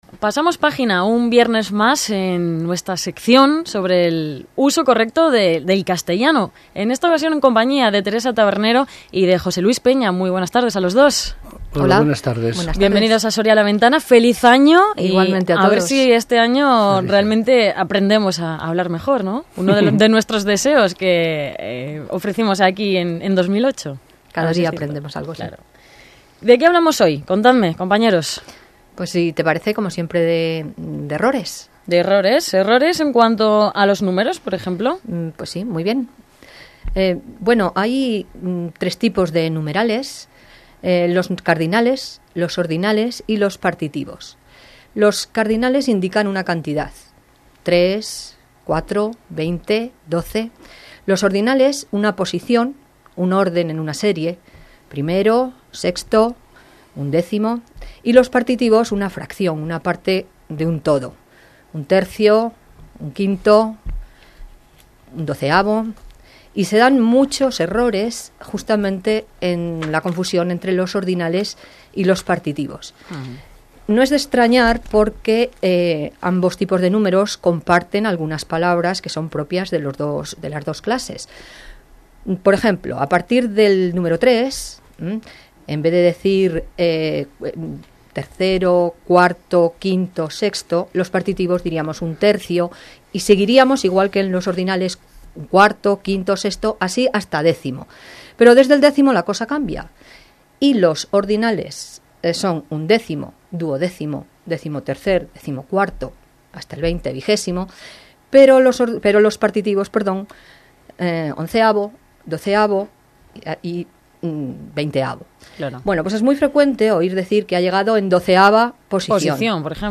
Escrito por administrador webmaster, viernes 13 de marzo de 2009 , 13:06 hs , en Programas de Radio
Primera intervención del nuevo año 2009 en el programa La Ventana de Cadena Ser Soria.